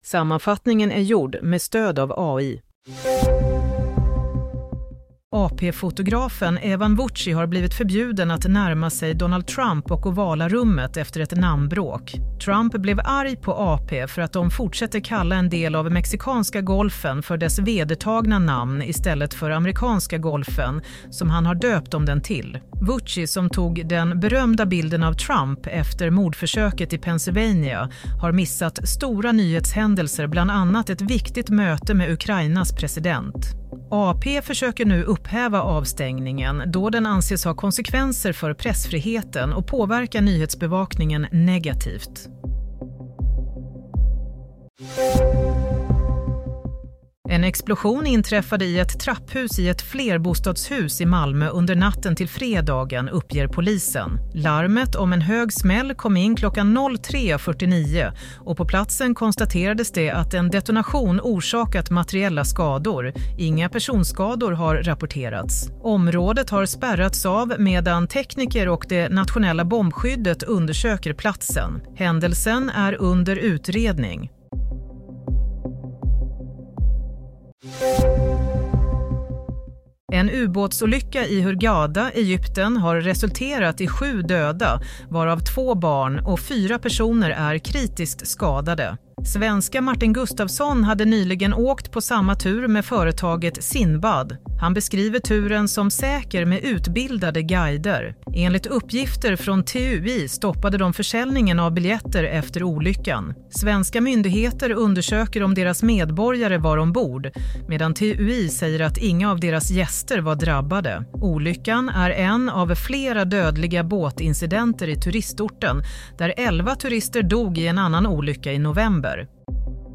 Nyhetssammanfattning - 28 mars 07:30